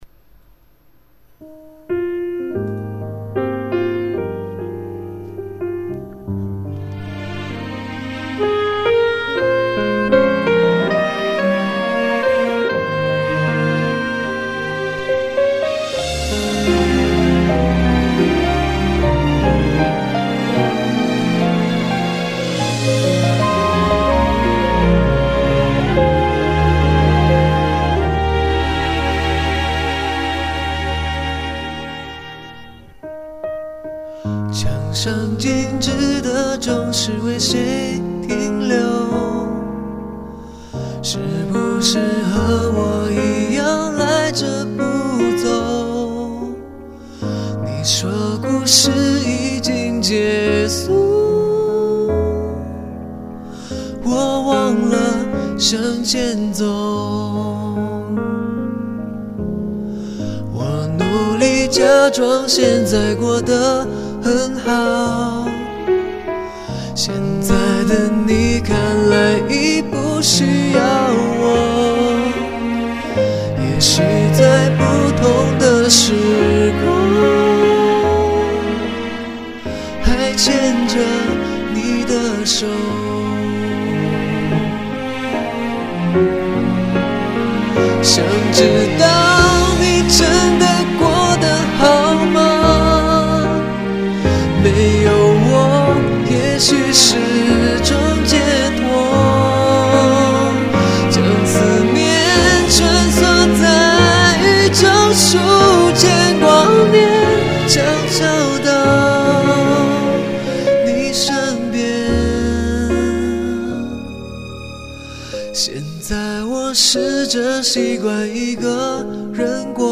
带着淡淡的悲伤以及思念，仿佛在诉说每一段刻苦铭心的爱情故事。